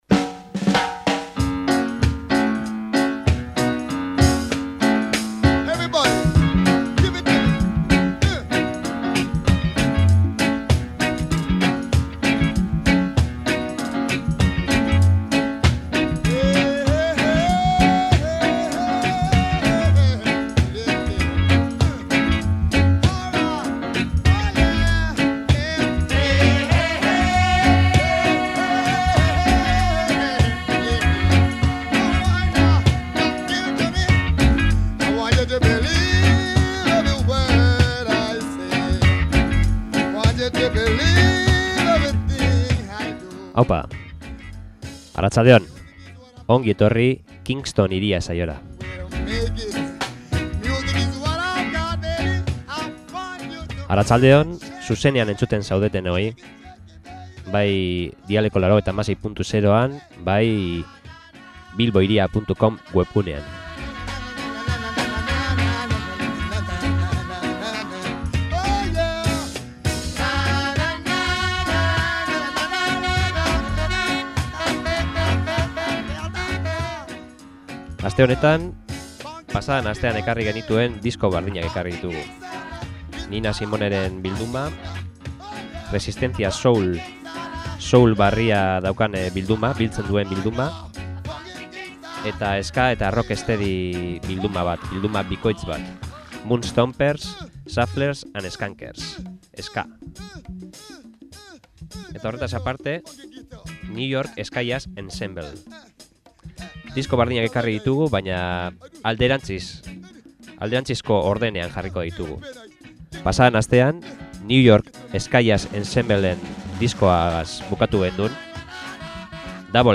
… ta Ska pila bat!